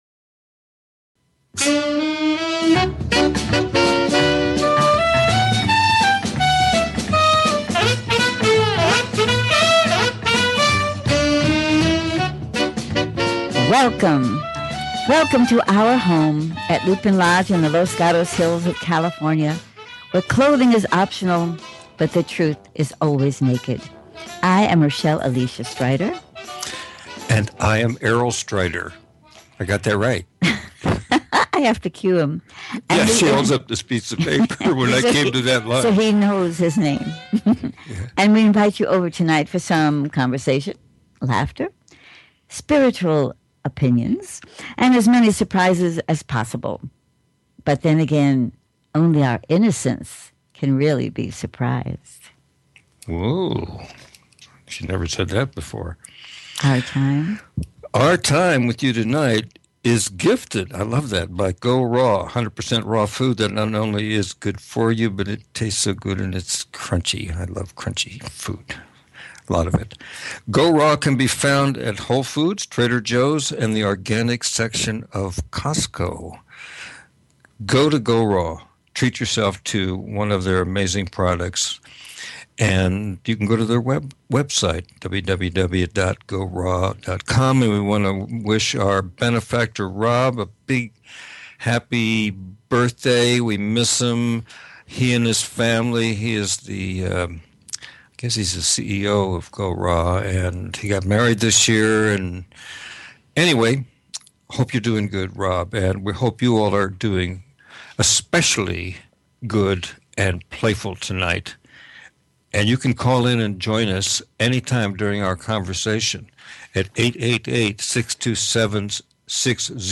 Talk Show Episode, Audio Podcast
There is always a plethora of humor possibilities that we plan to highlight tonight-especially in the contradictions of life and we plan to feature some very unique music, appropriate for the season, but a little out of the holiday box.